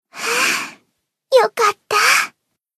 贡献 ） 分类:蔚蓝档案语音 协议:Copyright 您不可以覆盖此文件。
BA_V_Hinata_Tactic_Victory_1.ogg